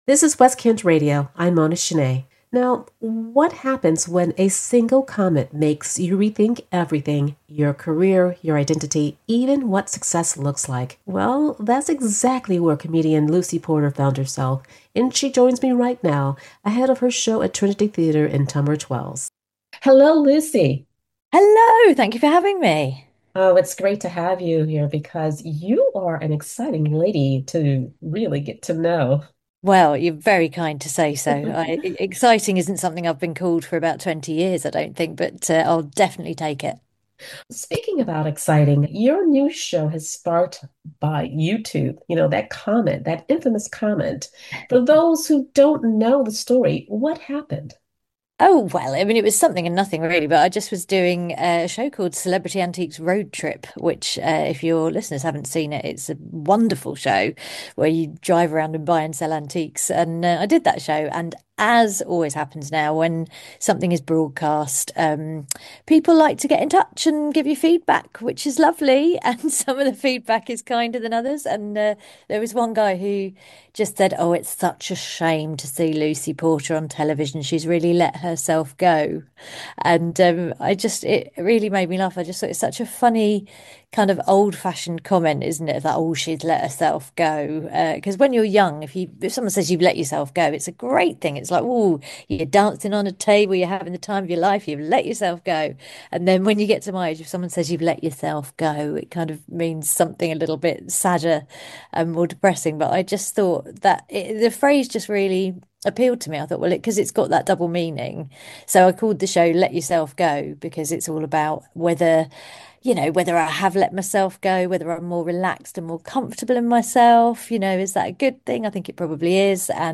More from INTERVIEW REPLAYS